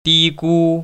[dīgū] 띠구